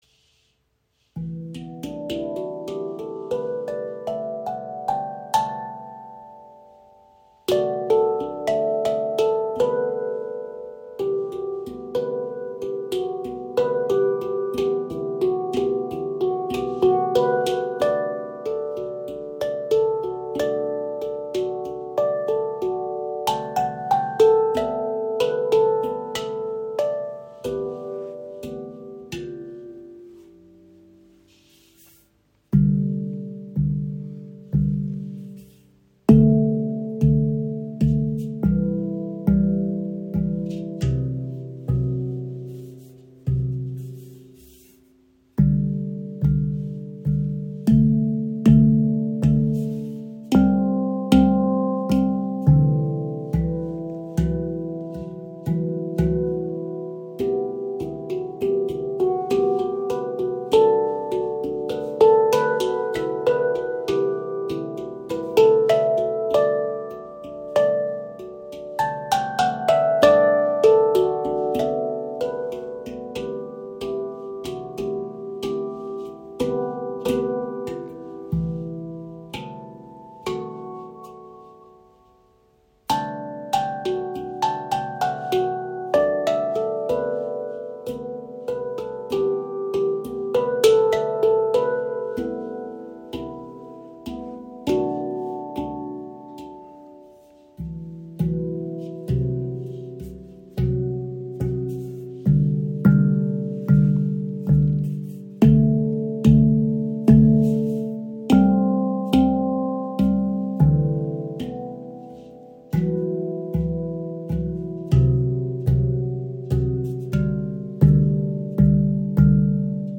Handgefertigte Edelstahl-Handpan mit offener, ruhiger Stimmung, tiefer Erdung, langem Sustain und fliessendem Klang – ideal für Meditation und Klangreisen.
Die E-Amara-Stimmung entfaltet eine ruhige, offene Klanglandschaft mit tiefer Erdung und sanfter Weite.
Der Charakter bleibt ruhig, tragend und ausgewogen – weder melancholisch noch dominant.
Töne: (C D) E – (F# G A) B (C) D E F# G A B (C) D E F# G A